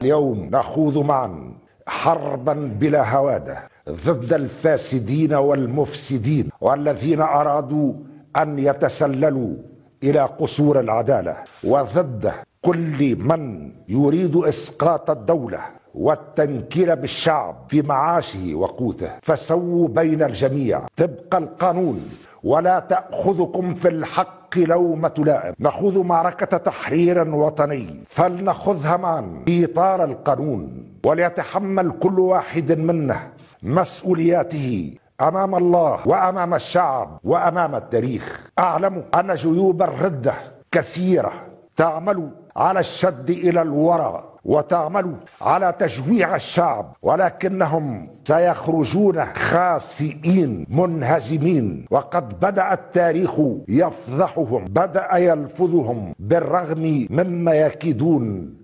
قال رئيس الجمهورية قيس سعيد، خلال إشرافه على موكب أداء اليمين من قبل أعضاء المجالس المؤقتة للقضاء العدلي والإداري والمالي، انه من يتصوّر مرة أخرى أنه سيُطوّع القضاء لفائدته فليعلم أنه سيصطدم بسد منيع هو القضاء العادل والمستقل.